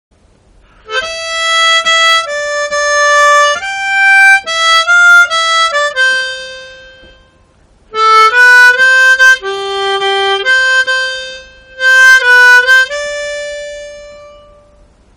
《フリスチューンβ》(shounenG)（音源）